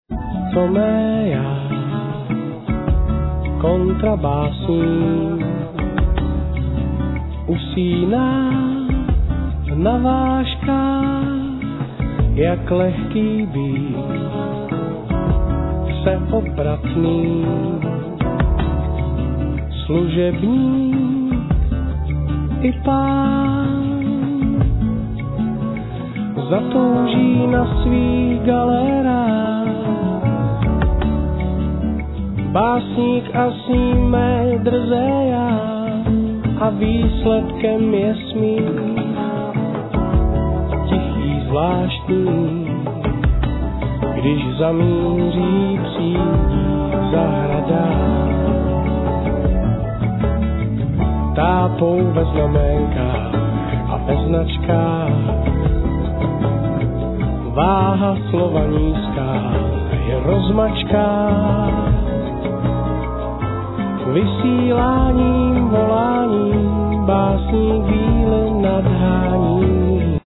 Strings:
Vocals
Guitar synthes,Ac.guitar samples
Fender piano,Djembes Egg,Synthes
Double. bass
Drums